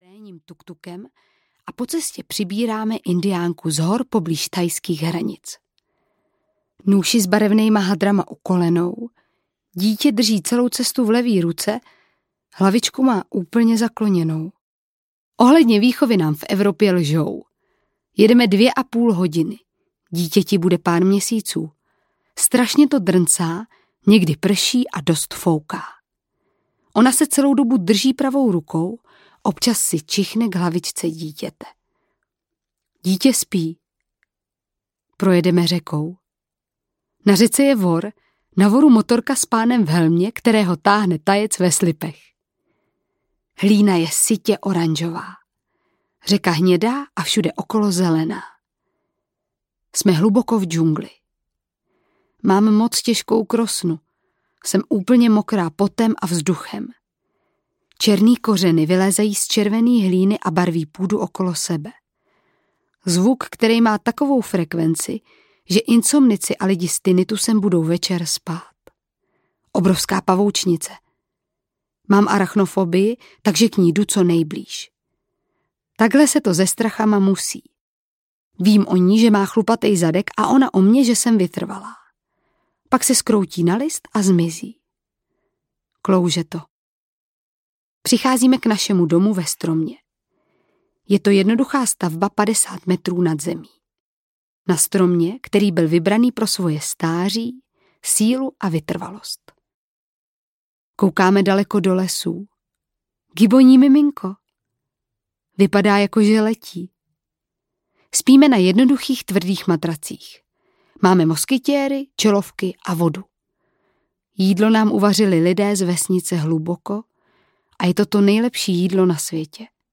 Tereza Ramba: DobroDruhům audiokniha
Ukázka z knihy
• InterpretTereza Ramba